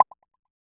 Drop Shape Into Place.wav